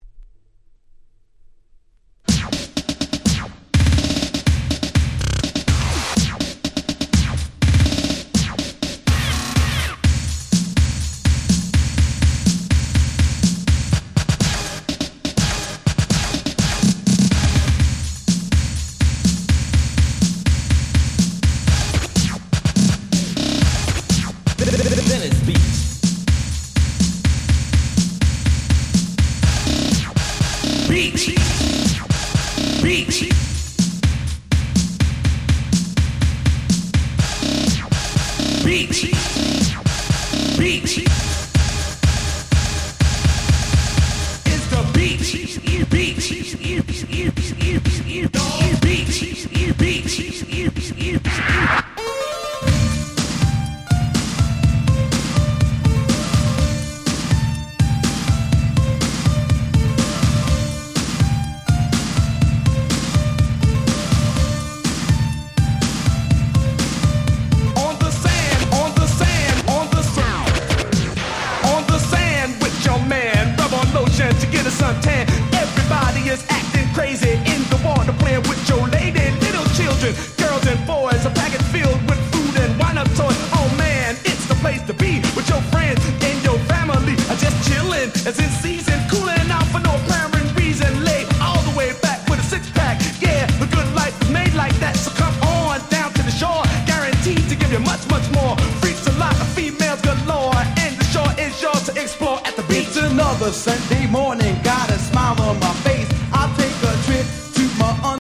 86' Old School Hip Hop Classic !!